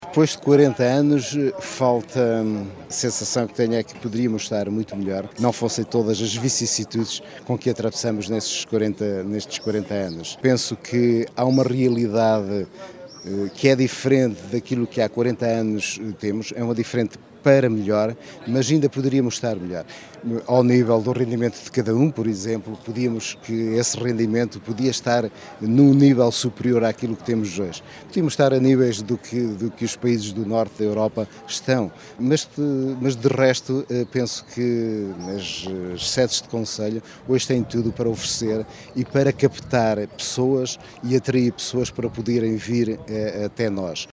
Declarações esta manhã, nas comemorações oficiais do 25 de Abril, que começaram domingo à noite, com o concerto “Fado e Cantigas de Abril”.